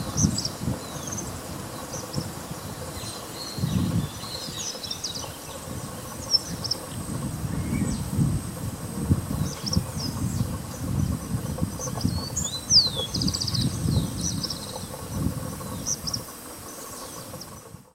Uí-pi (Synallaxis albescens)
Nome em Inglês: Pale-breasted Spinetail
Fase da vida: Adulto
Localidade ou área protegida: Reserva Natural del Pilar
Certeza: Gravado Vocal
pijui-cola-parda.mp3